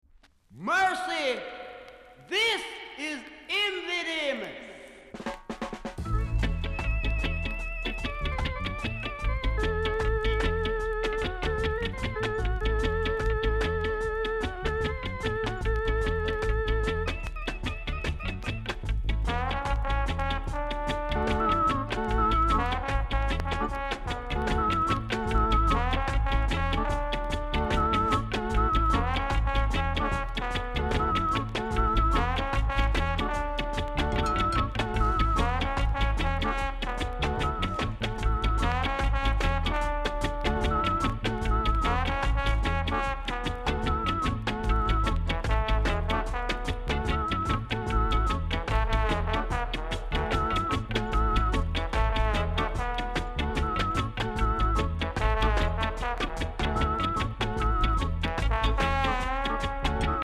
※小さなチリ、パチノイズが少しあります。盤は細かい薄い擦り傷、小傷が少しあります。
KILLER SKINS INST!!